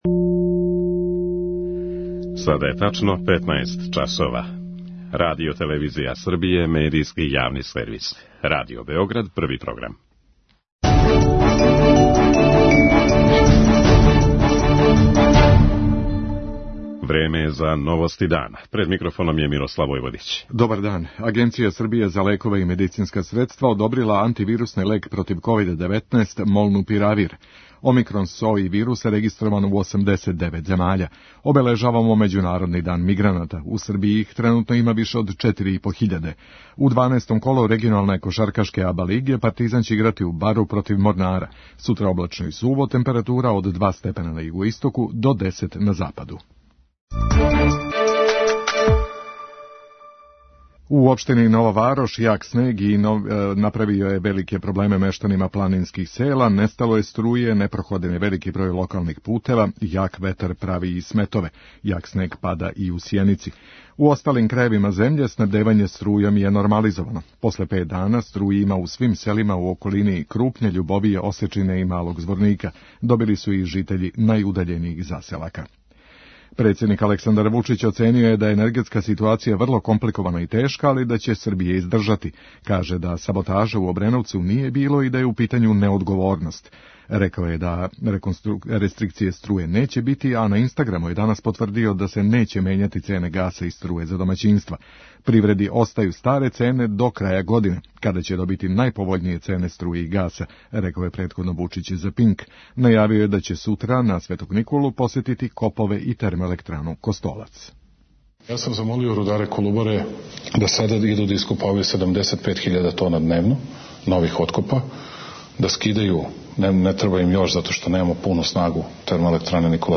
централна информативна емисија Првог програма Радио Београда